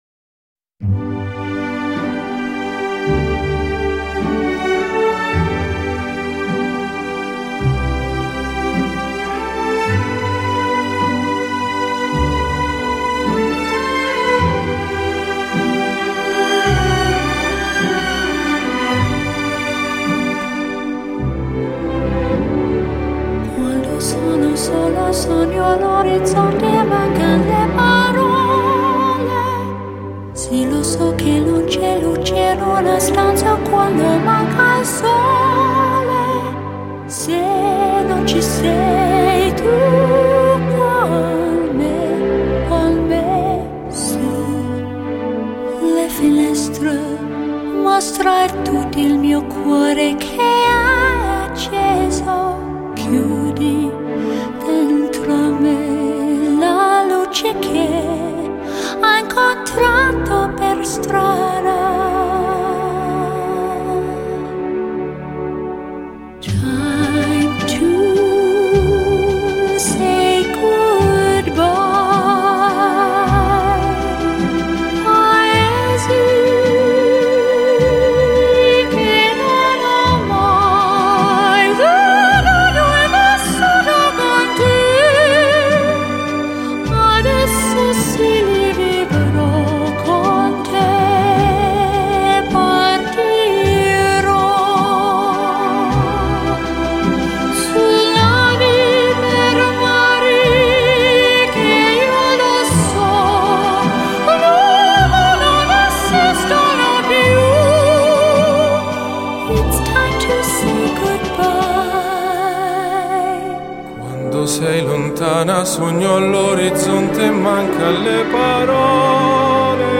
不同的语言，不同音色的人声，各异的演唱风格，带给你多姿多彩的音乐享受，